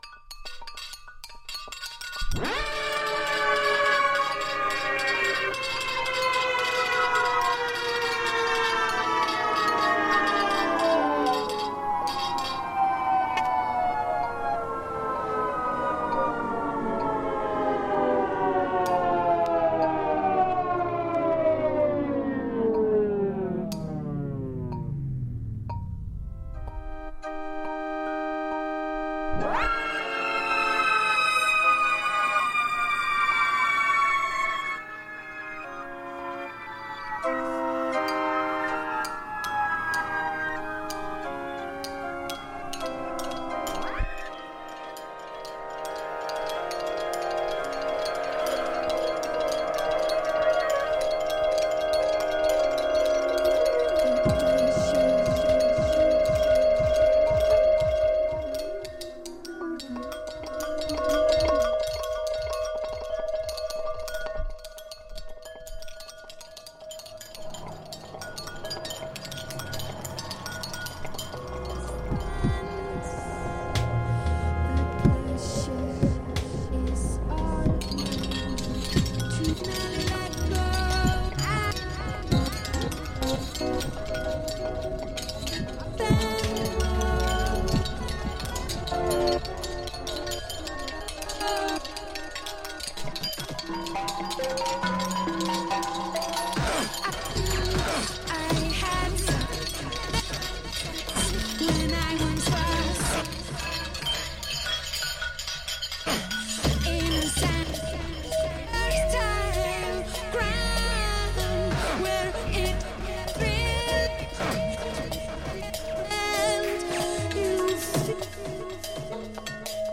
In a stew of intimate electronics, bad poetry, and tender murk, we swap tongues and reach for more.